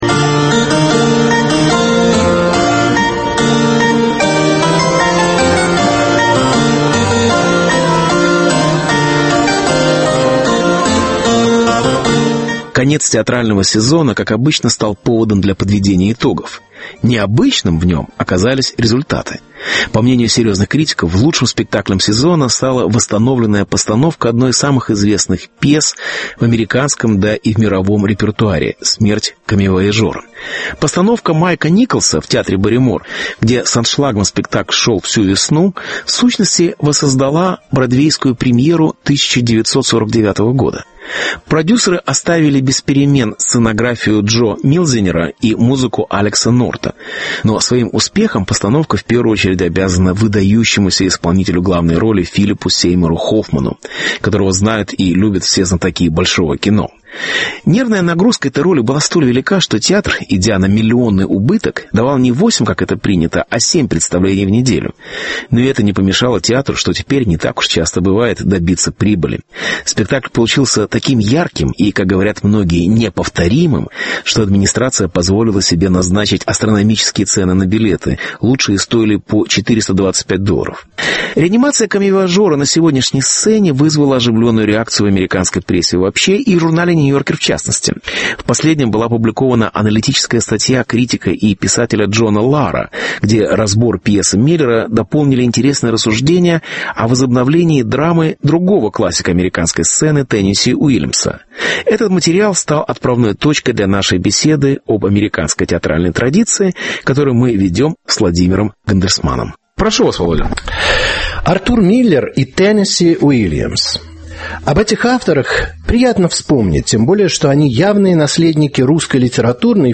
Смерть - и возрождение - коммивояжера. (Беседа